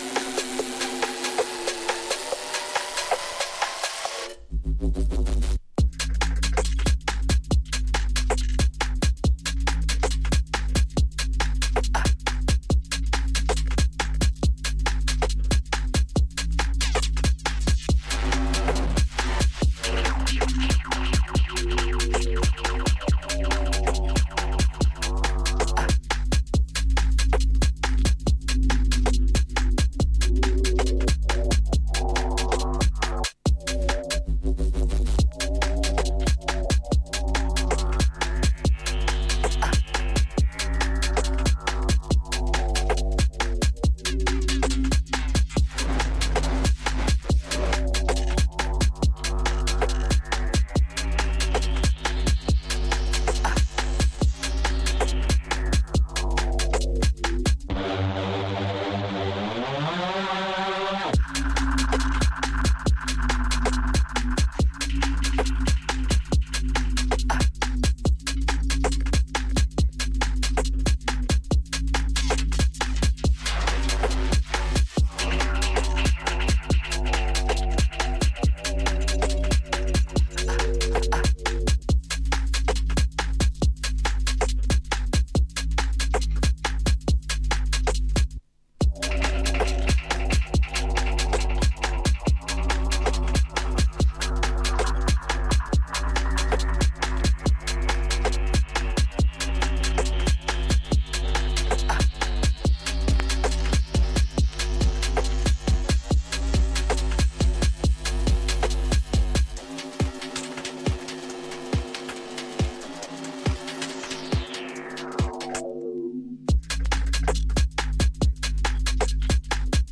昨今のUKG新世代のスピード感に合わせるべく更にテンポを上げて140BPMで攻めるストイック骨組み系ミニマル・ガラージ